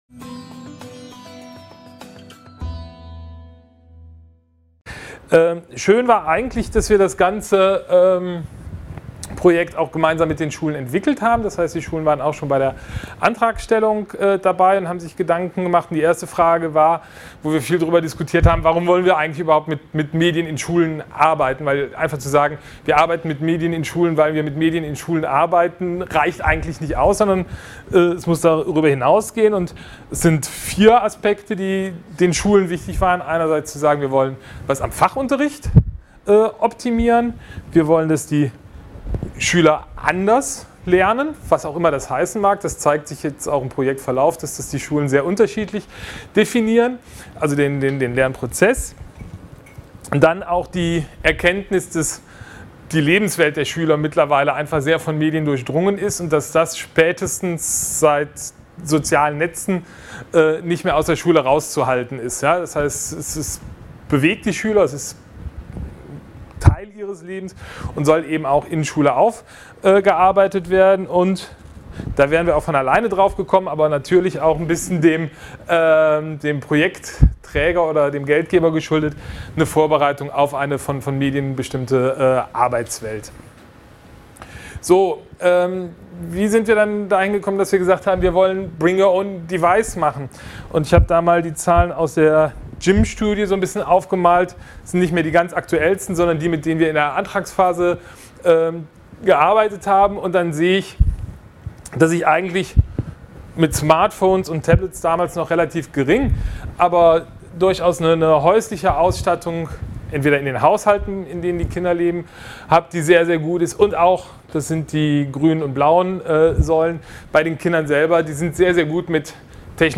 1. Vortrag: Ein paar Anmerkungen zum Lernen mit (mobilen) persönlichen Endgeräten & Organisatorisches